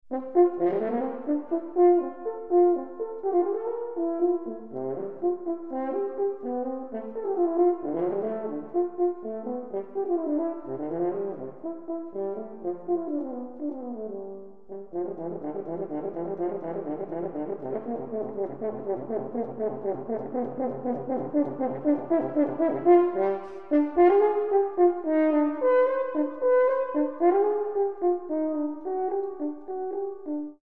Horn
Iwaki Auditorium, ABC Southbank, Melbourne